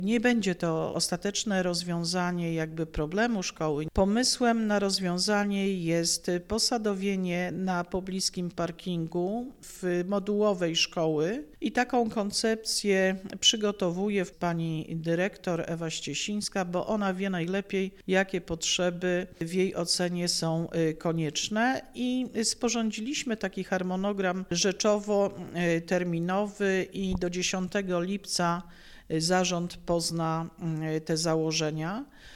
Dobrą wiadomość przekazuje Iwona Wiśniewska – Starosta Powiatu Stargardzkiego, która zdradza szczegóły planowanego rozwiązania: